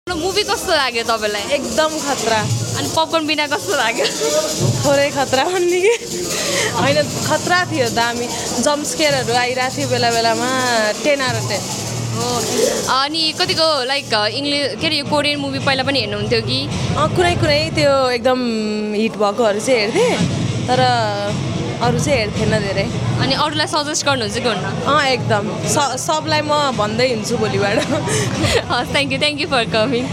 Realeased from Today in Nepals Cinema Hall